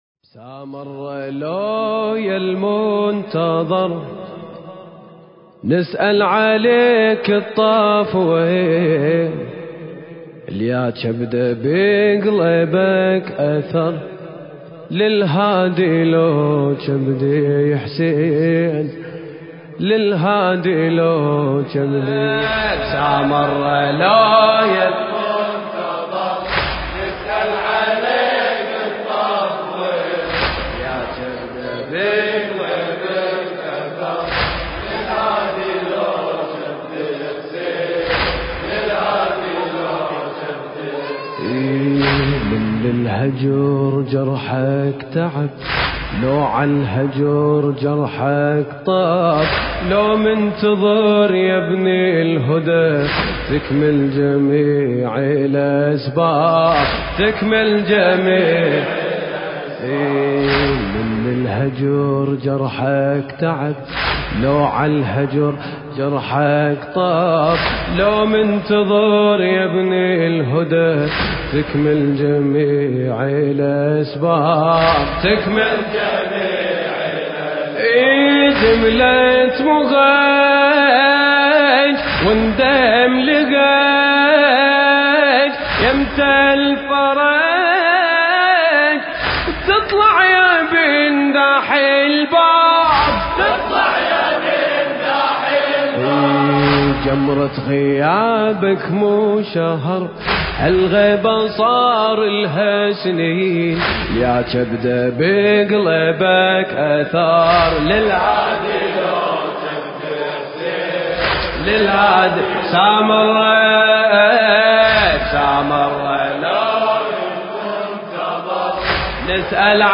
حسينة سيد الشهداء (عليه السلام)/ دولة الكويت